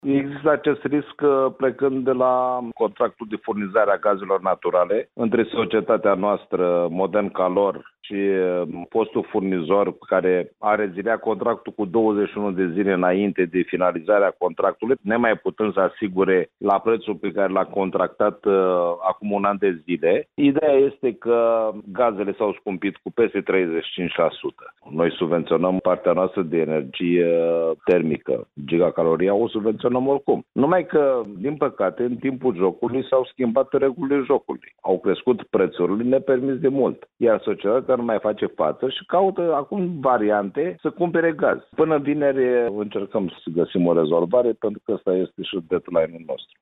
Acesta a declarat, într-o conferinţă de presă, că societatea furnizoare de gaz a decis întreruperea relaţiilor contractuale cu Modern Calor cu 21 de zile înainte de expirarea contractului, ca urmare a scumpirii gazului şi refuzului de a livra produsul la preţul prevăzut în contract.